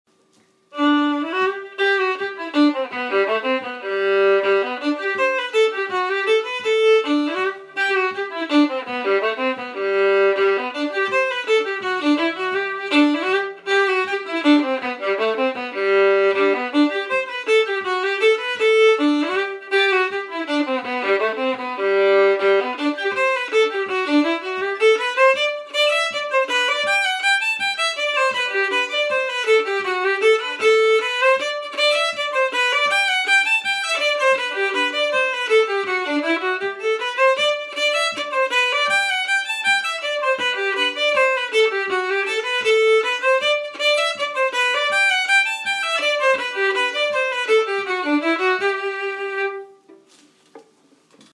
Key: G
Form: Reel
Region: Québecois/Acadian
Esquimeaux-square-version.mp3